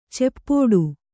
♪ ceppōḍu